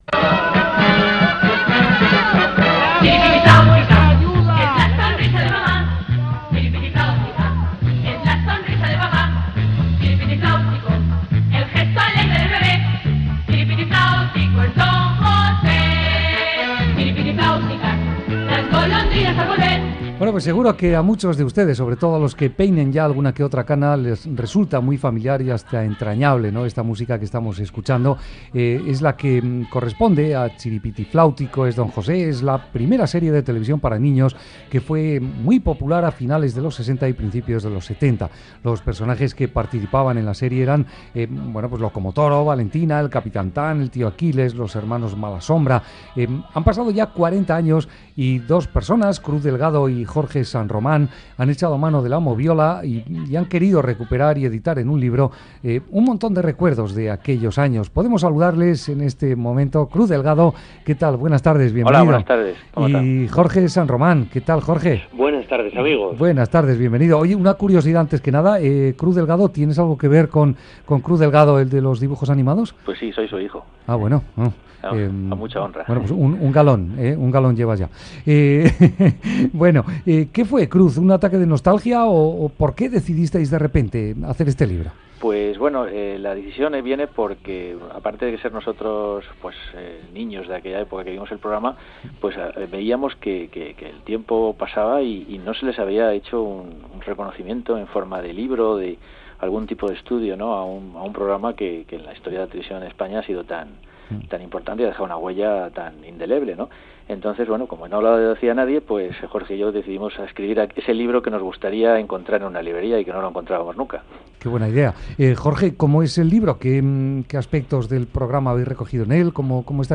‘Chiripitiflautico es Don José’, libro sobre la serie | Entrevista